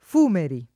[ f 2 meri ]